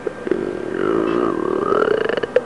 Door Creak Sound Effect
Download a high-quality door creak sound effect.
door-creak.mp3